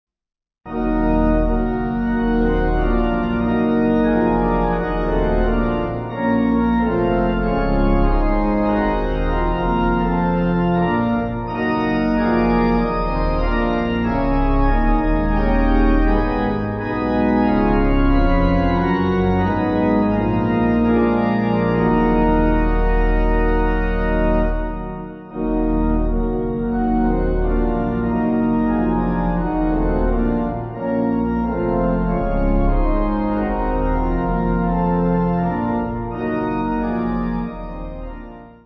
(CM)   3/Ebm